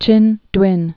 (chĭndwĭn)